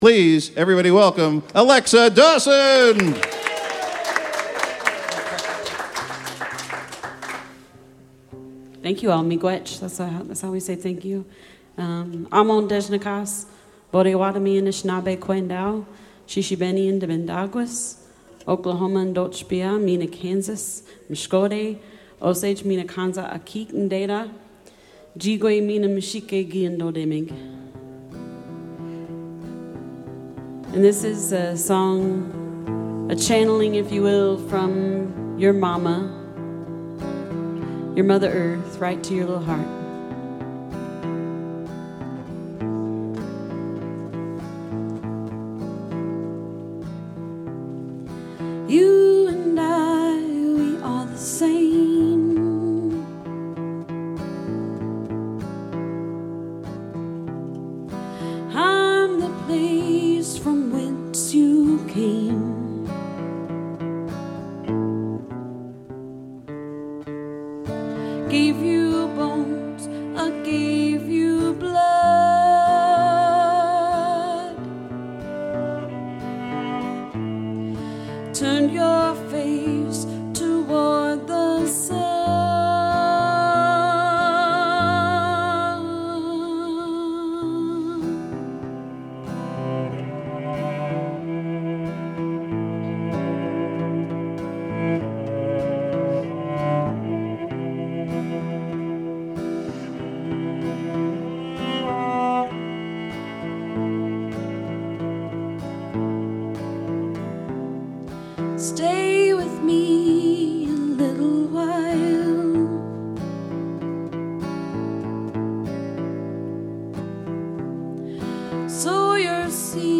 cello
guitar